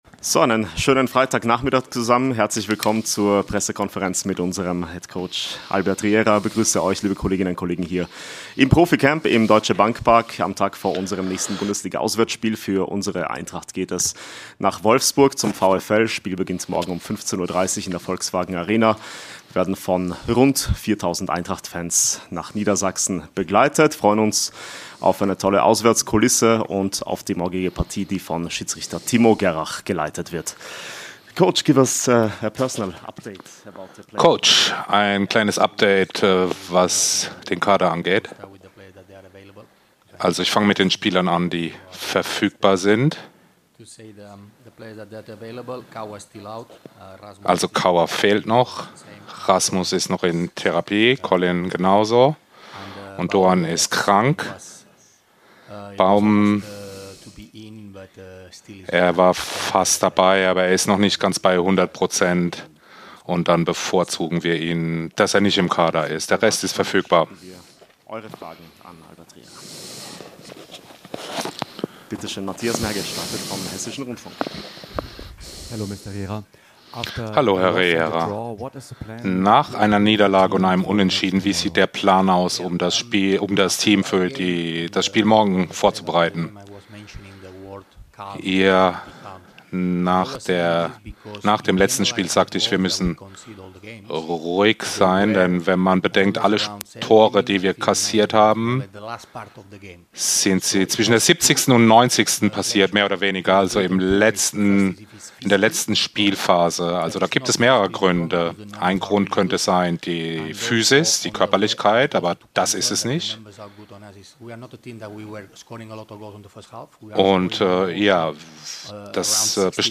Die Pressekonferenz vor dem Bundesliga-Auswärtsspiel beim VfL Wolfsburg mit Cheftrainer Albert Riera.